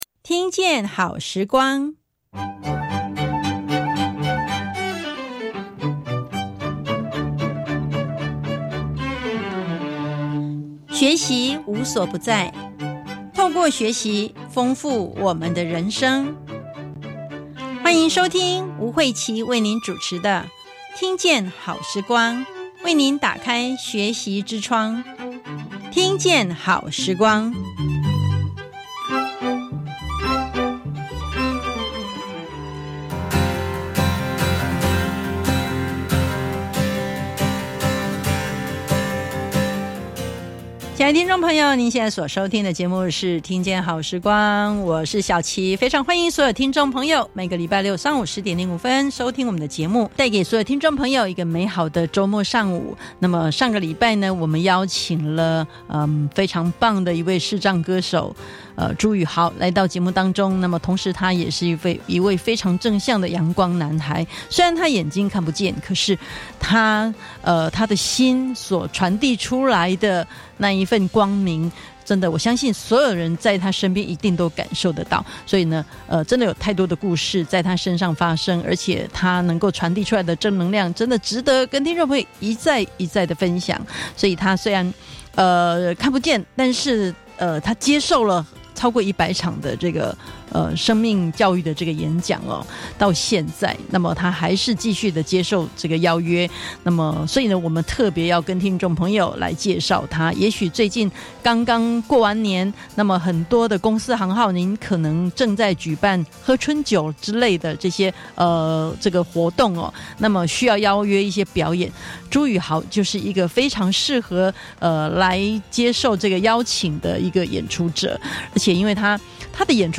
英文老師 國立教育廣播電台線上收聽請點此 以上為國立教育廣播電台官方網站，內容有每集節目音檔，60天內都可以隨選收聽。